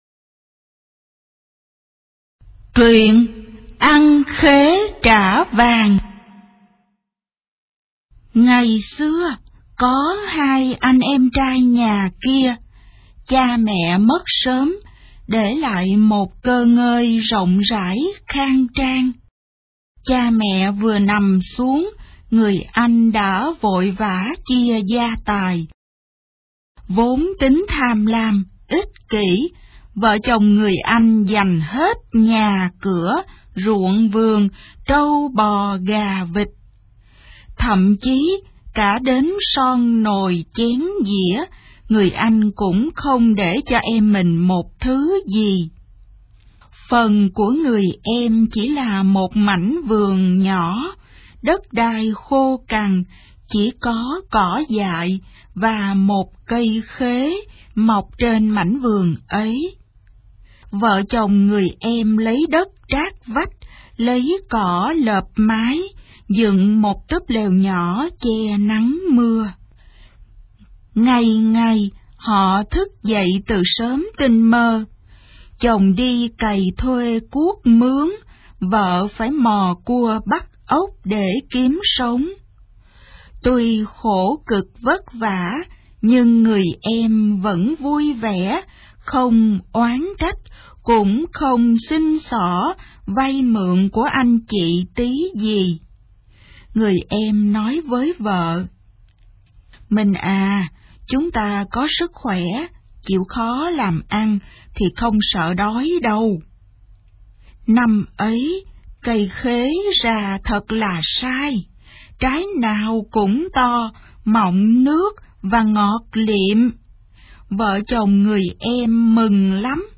Sách nói | 27_TRUYEN_CO_TICH_VN27